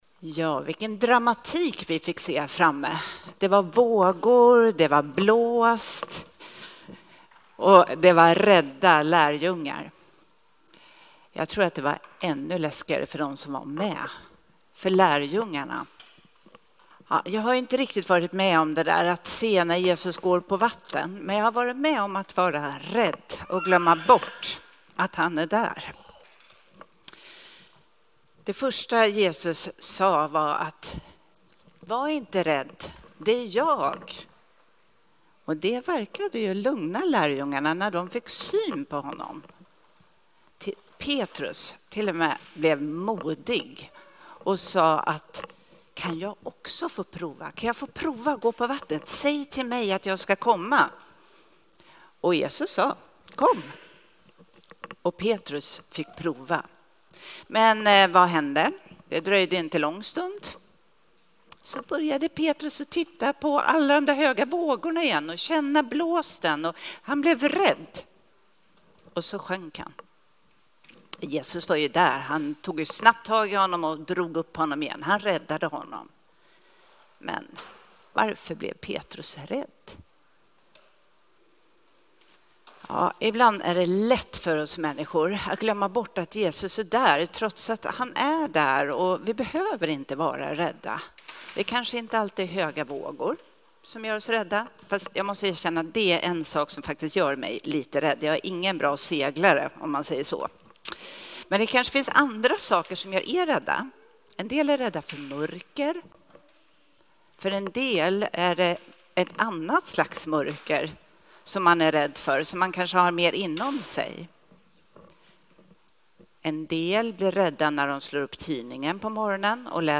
Predikan från gudstjänst med alla åldrar.